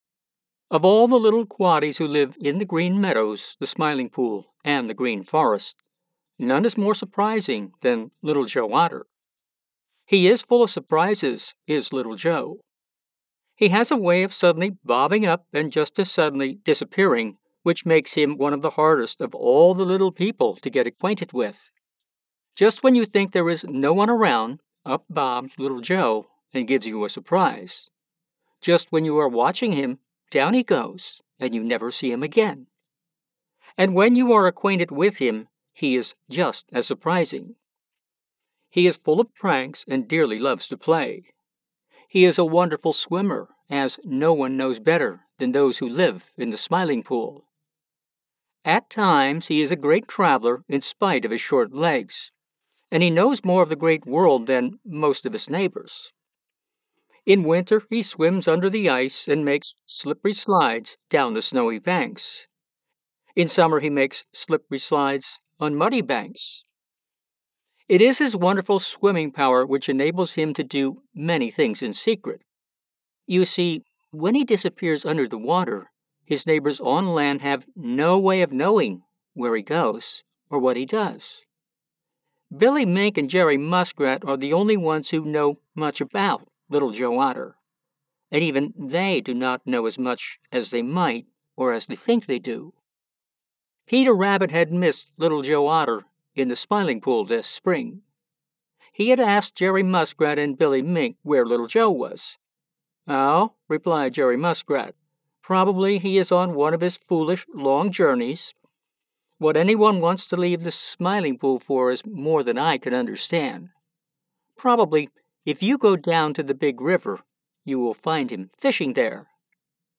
Children's and adult audiobooks